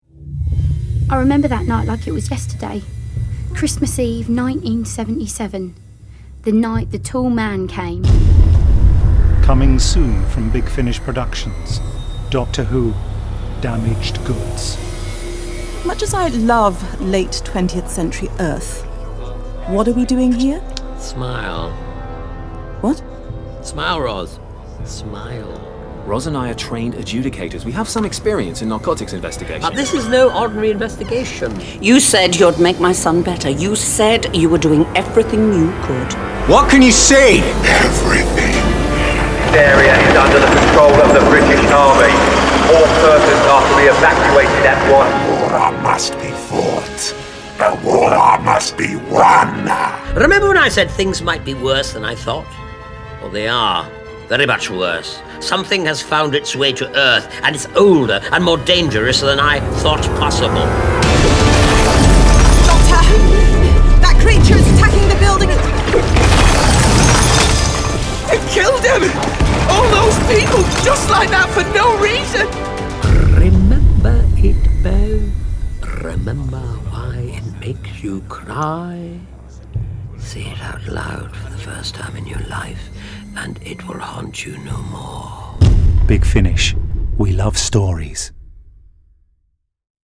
Starring Sylvester McCoy